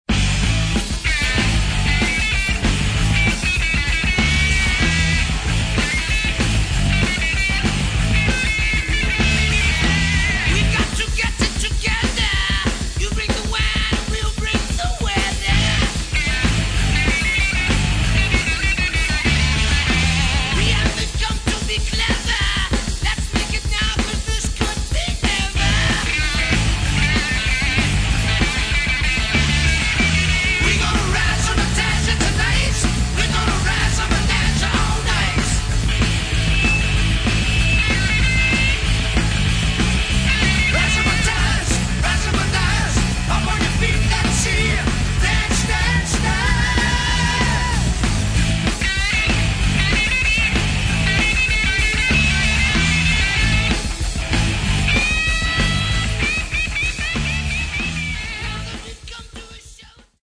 Рок
Великолепны все партии – вокал, гитары, барабаны.